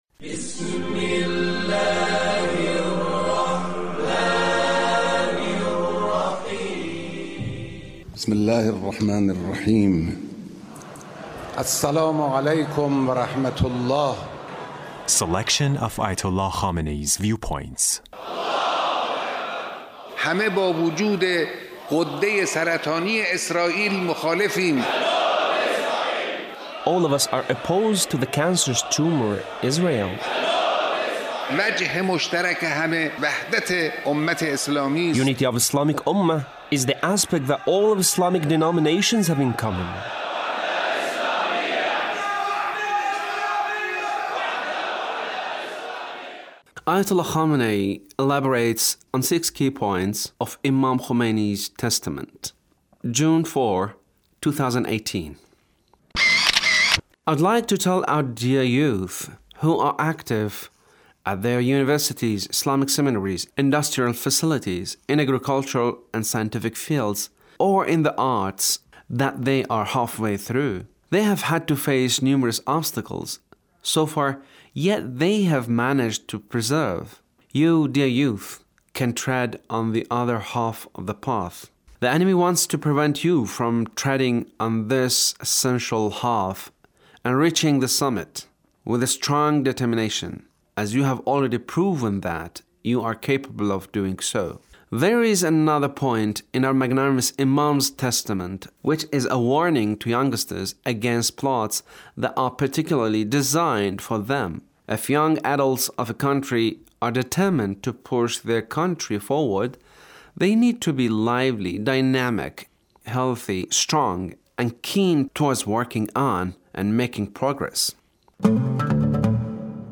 Leader's speech (1421)